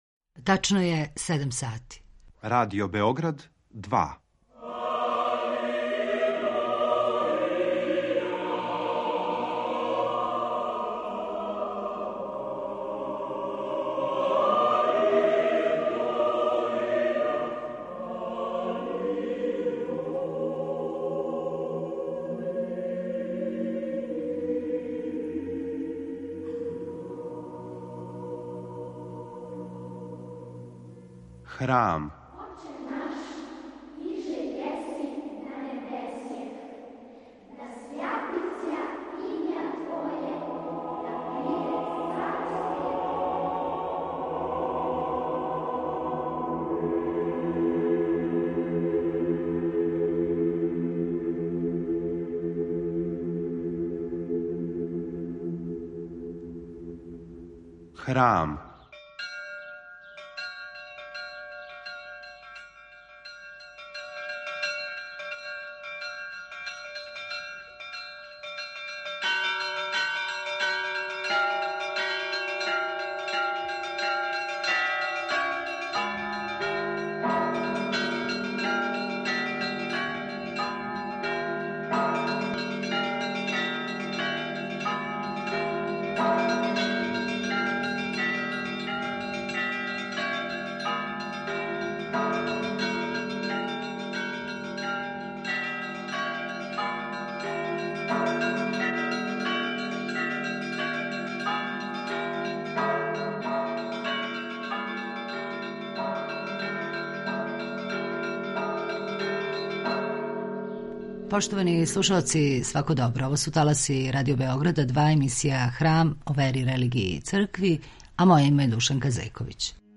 Гост је историчар